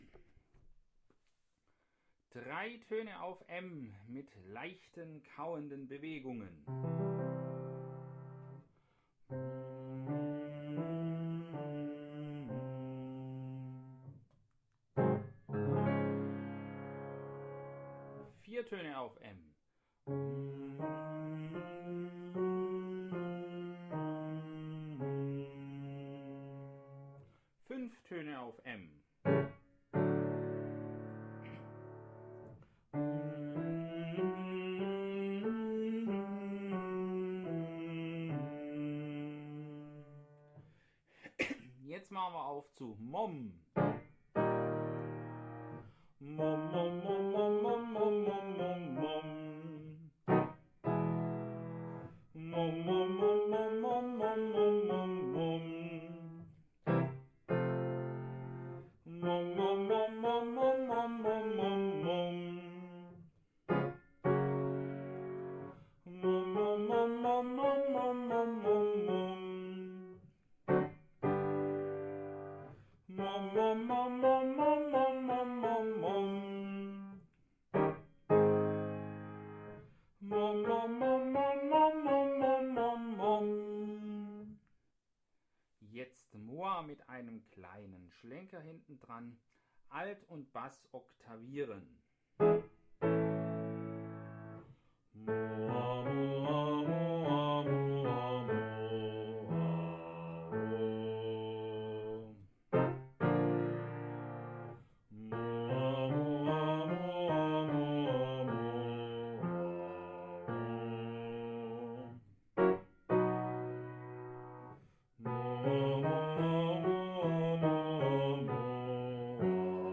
Einsingen 1. rauf